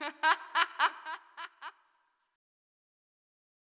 Metro Laughter 4.wav